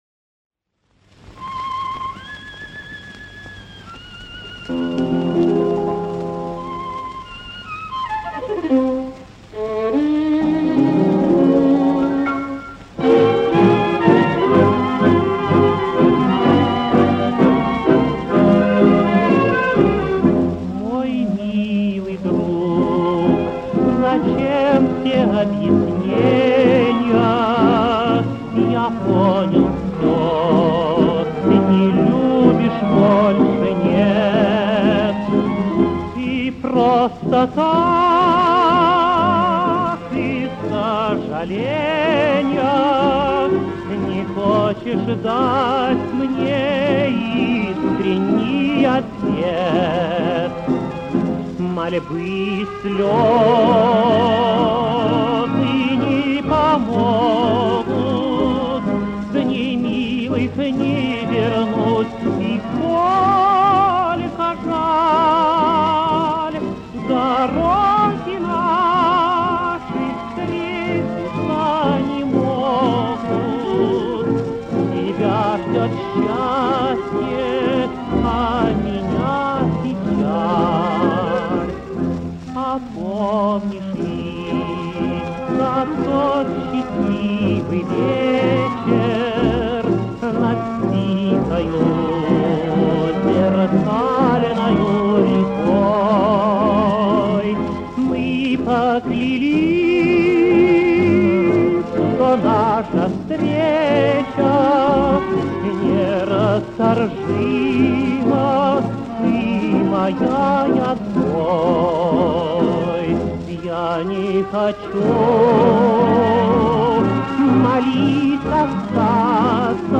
Каталожная категория: Тенор с джаз-оркестром |
Жанр: Танго
Вид аккомпанемента:    Джаз-оркестр
Место записи:    Москва |